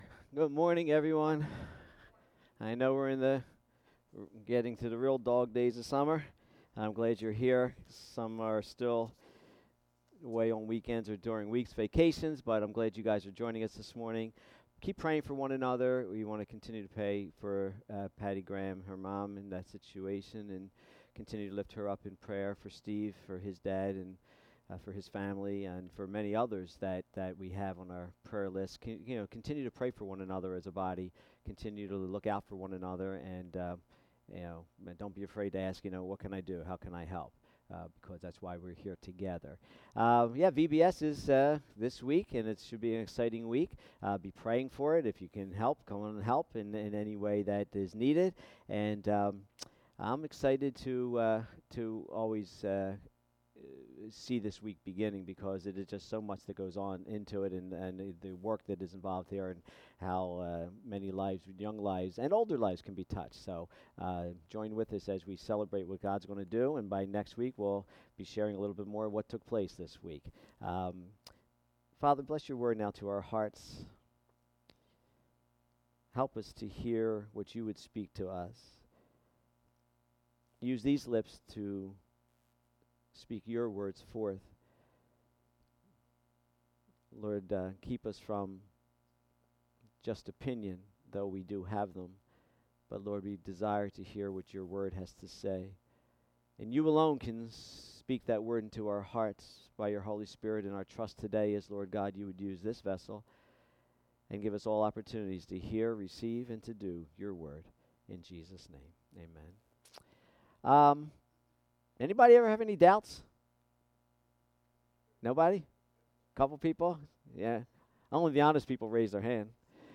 Sermons | Bethel Christian Church